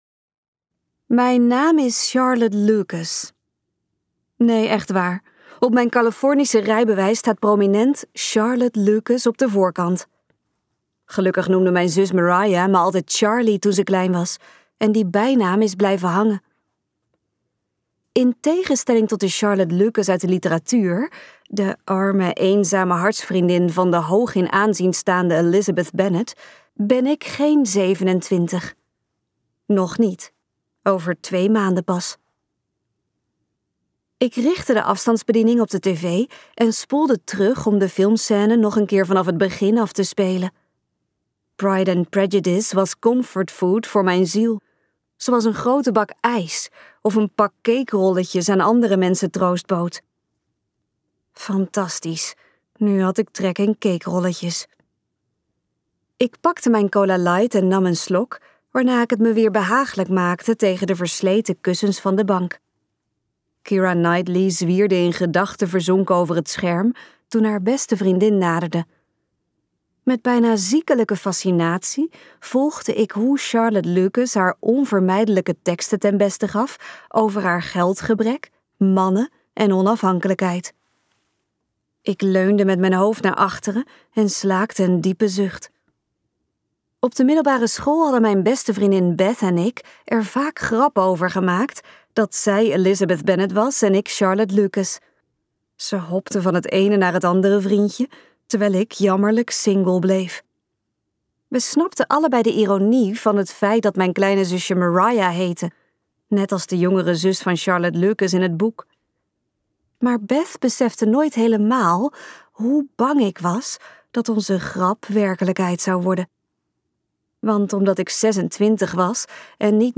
KokBoekencentrum | Verstrikt in fictie luisterboek